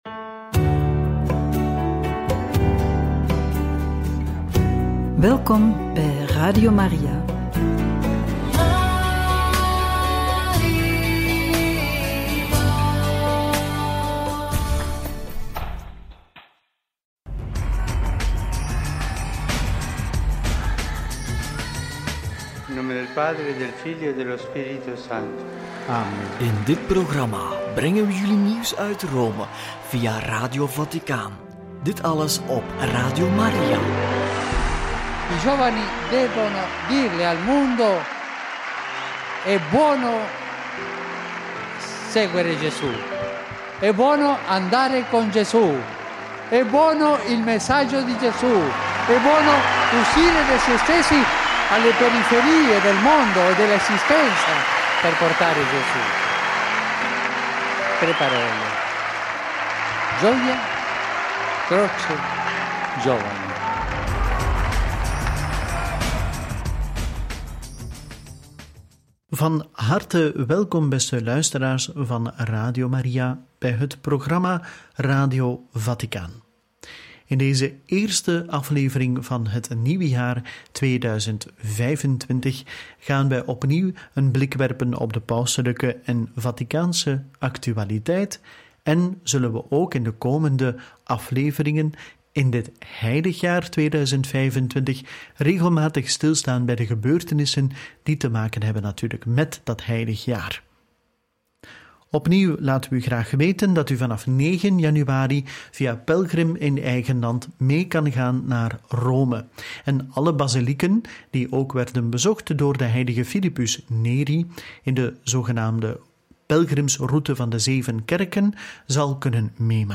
Paus Franciscus bidt plechtige Vespers en celebreert Eucharistie voor het hoogfeest van de Moeder Gods – Radio Maria